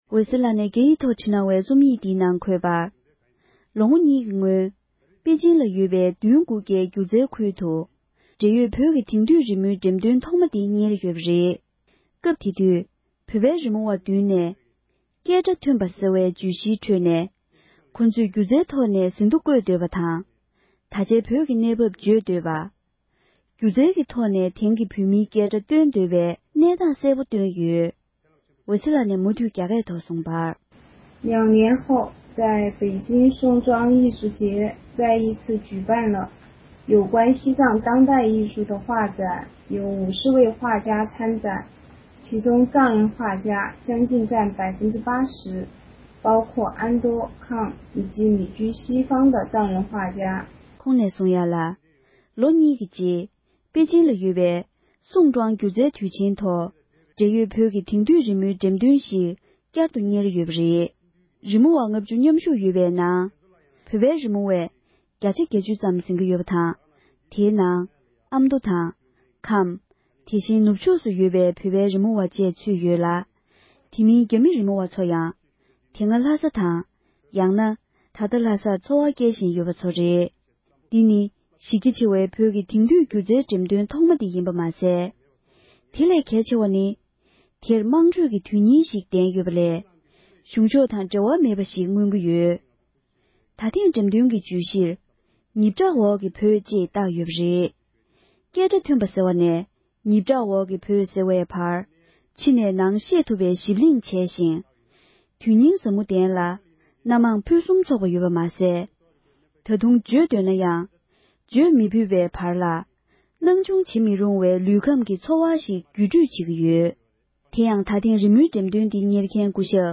ཕབ་བསྒྱུར་དང་སྙན་སྒྲོན་ཞུས་པར་གསན་རོགས༎